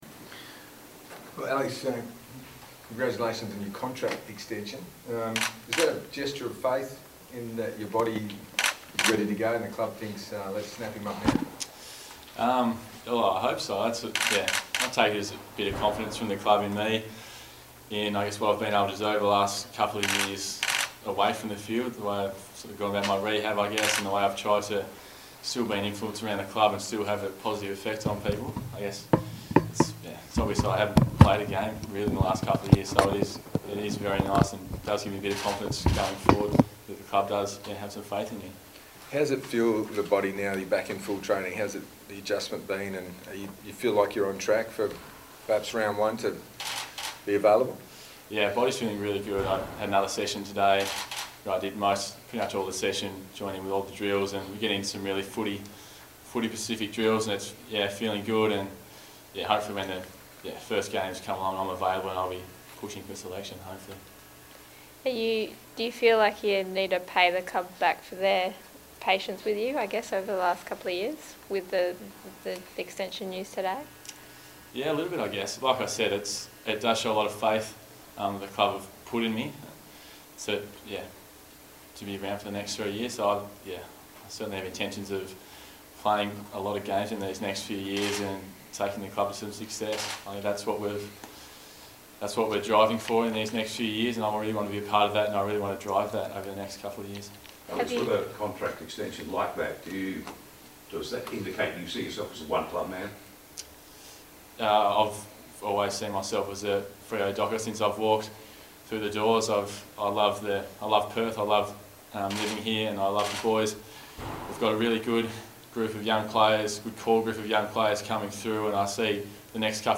Alex Pearce media conference - Monday 8 January 2018